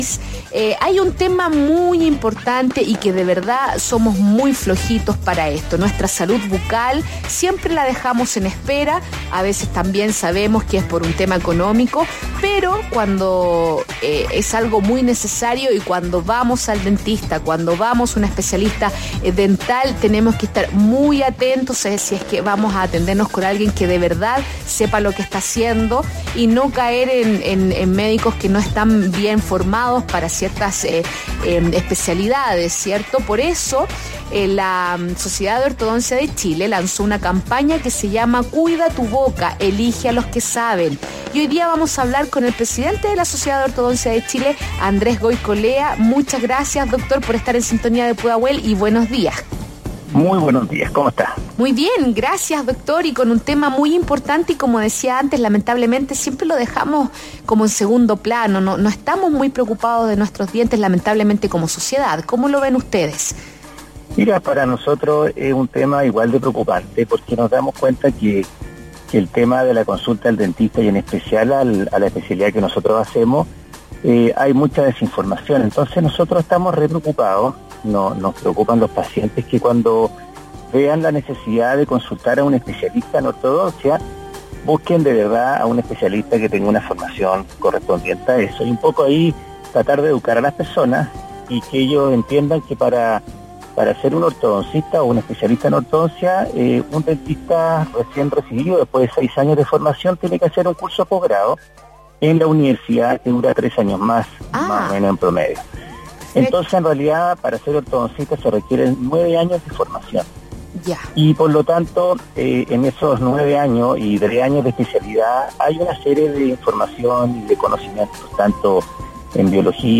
03 Jul Entrevista Programa «El Ciudadano ADN»